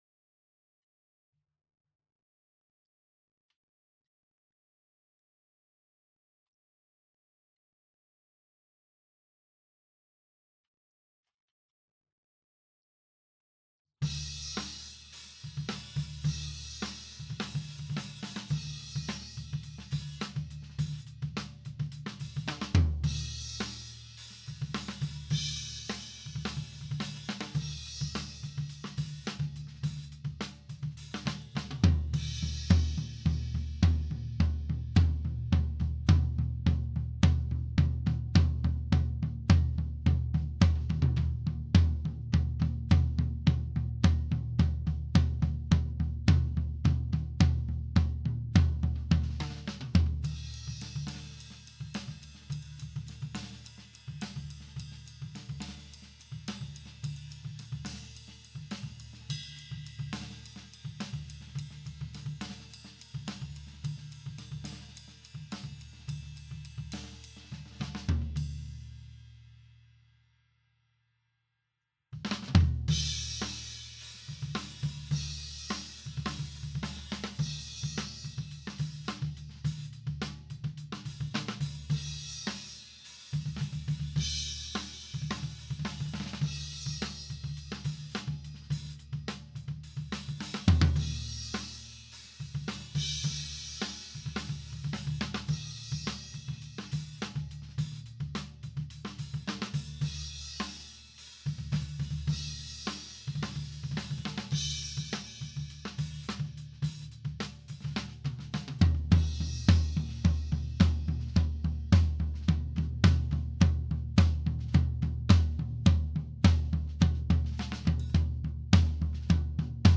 Sounds terrible without room mics or overheads blended but just focus on the rumble. There's no compression or gating on this so you can hear the issue. If you listen to the part where I am playing 1/8 notes, that's the verse.
Then there are the other fills in the rest of the song which are pretty much all harder hits.